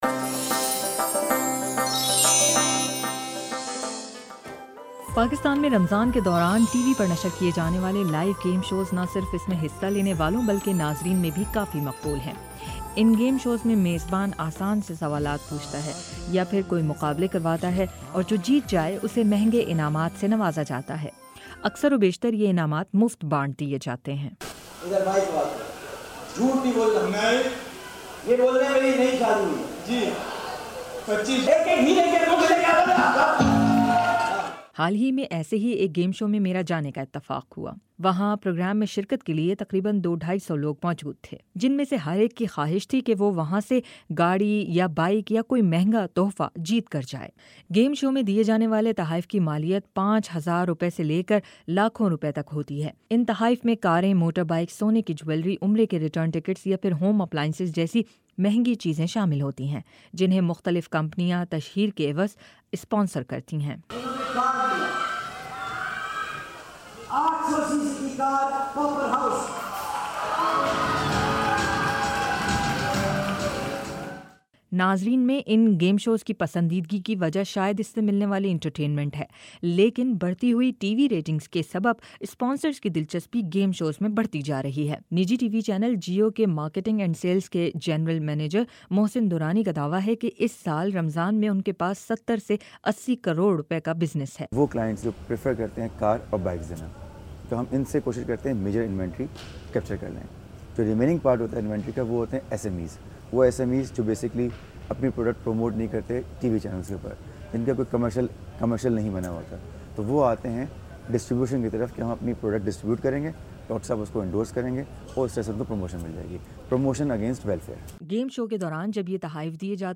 رپورٹ۔